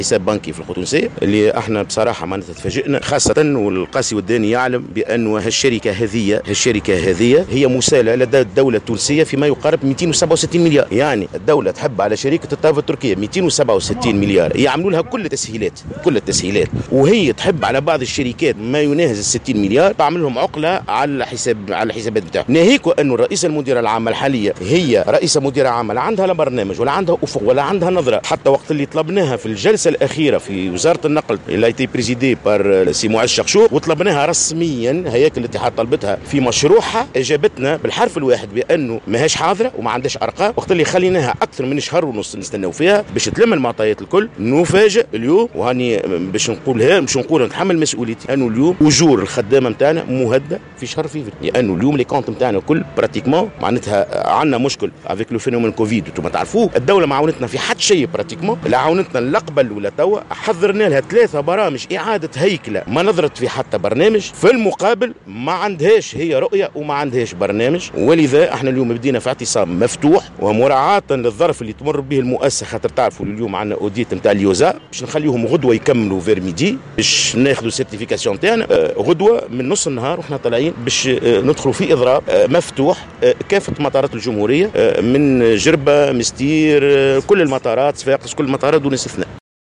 في تصريح لمراسلة "الجوهرة أف أم"